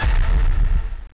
OOMPH.WAV